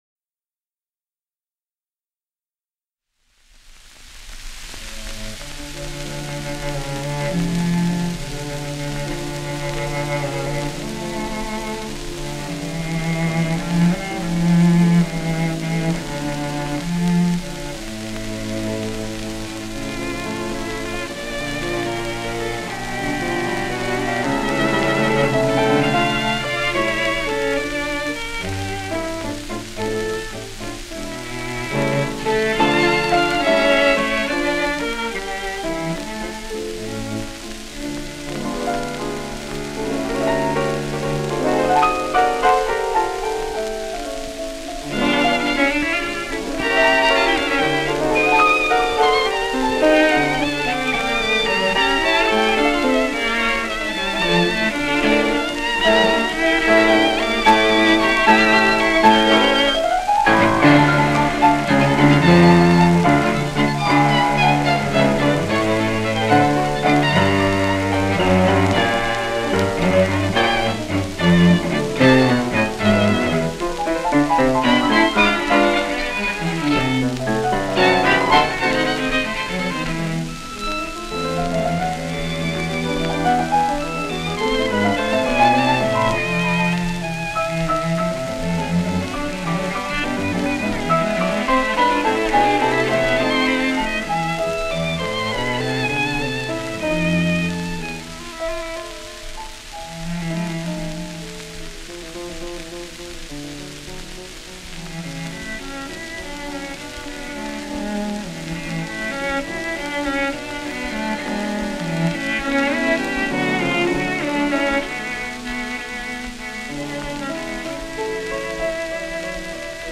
The wildly emotional Cortot matched his passionate strokes to Thibaud’s unmistakable class and Casals (a future conductor) supported the entire enterprise and allowed the cello parts to shine fully.